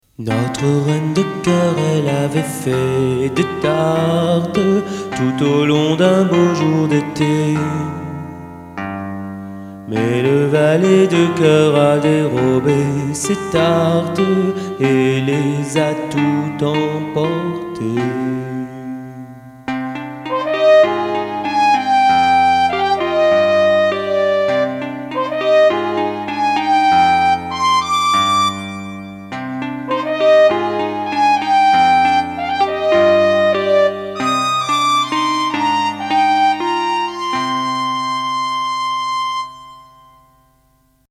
Comptine de ma mère l’oie « Reine de coeur »